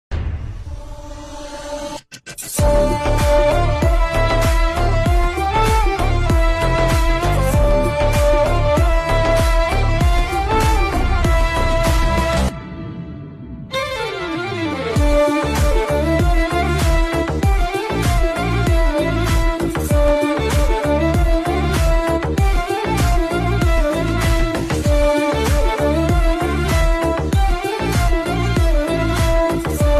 With its catchy melody and vibrant rhythm